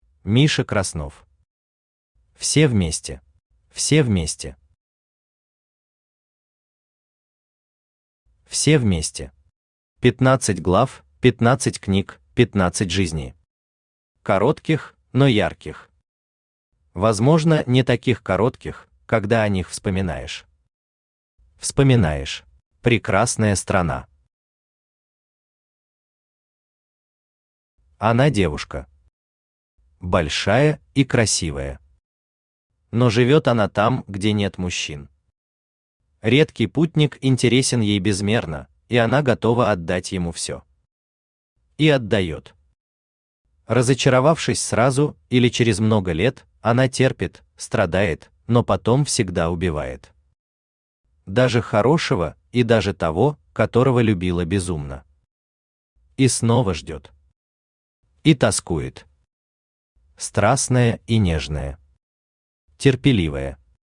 Aудиокнига Все вместе Автор Миша Краснов Читает аудиокнигу Авточтец ЛитРес.